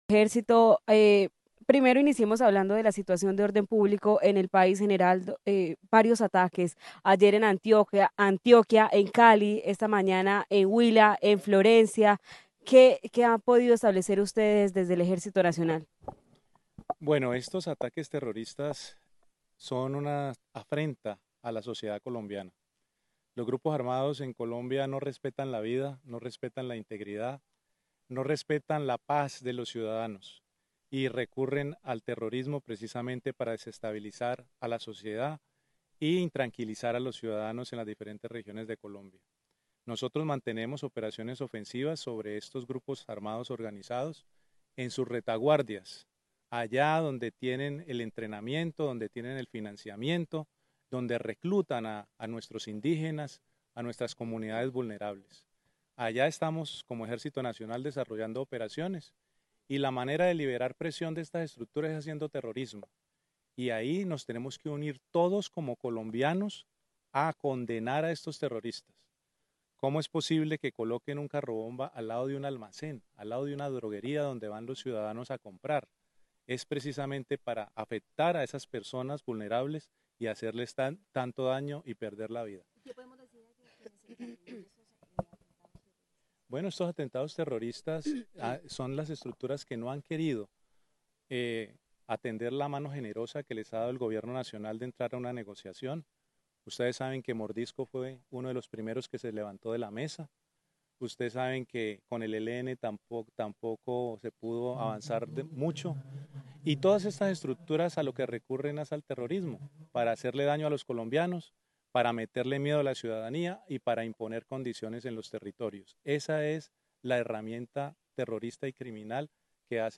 General Luis Emilio Cardozo, comandante Ejército Nacional